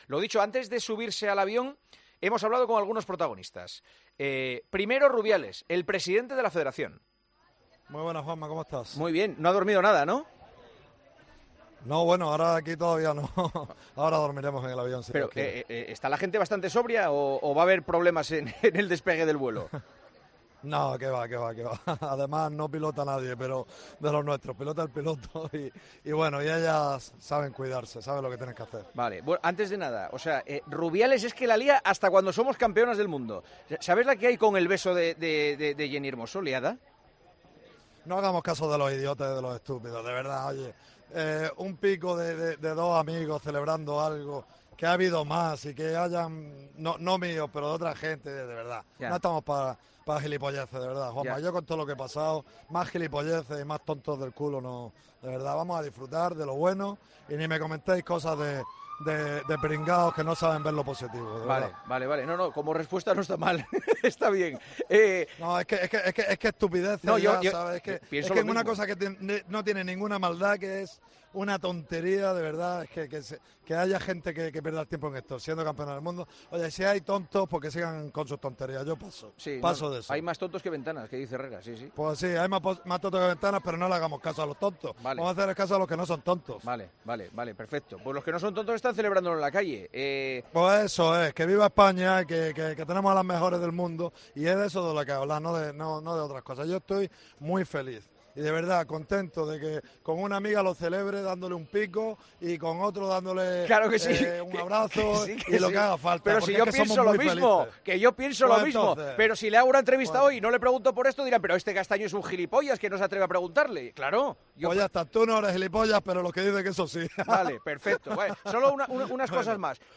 El presidente de la RFEF, Luis Rubiales, atendió a Tiempo de Juego después de que España conquistara este domingo el Mundial de Australia y Nueva Zelanda y se mostró "muy feliz".